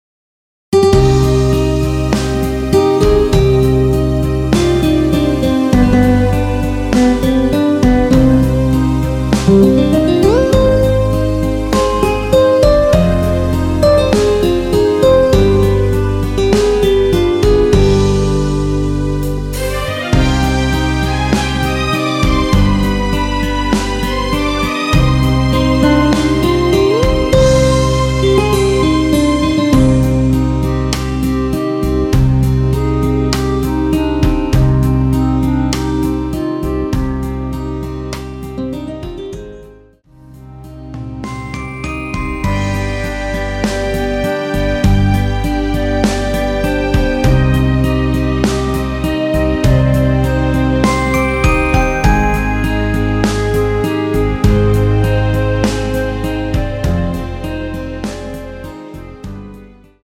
원키에서(-2)내린 멜로디 포함된 MR입니다.
Cm
앞부분30초, 뒷부분30초씩 편집해서 올려 드리고 있습니다.
중간에 음이 끈어지고 다시 나오는 이유는